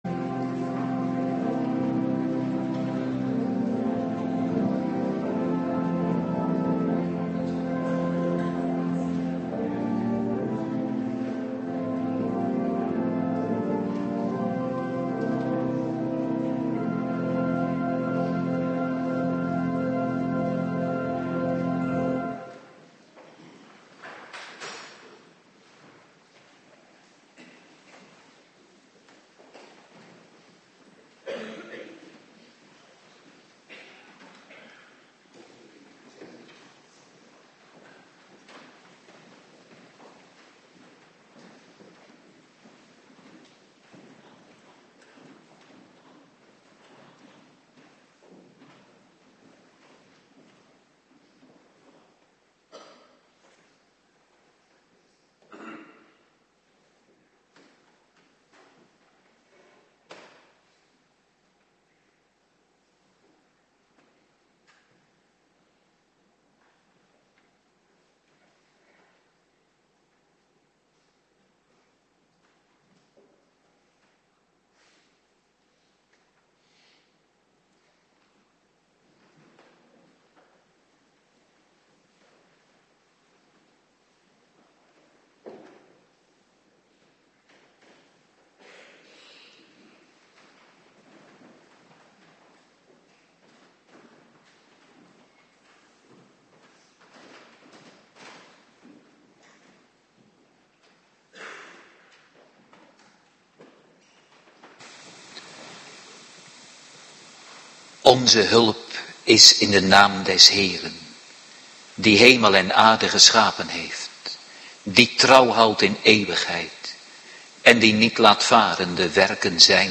Avonddienst Dankdag
19:30 t/m 21:00 Locatie: Hervormde Gemeente Waarder Agenda